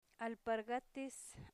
alpaɾ'gates
alpargates.mp3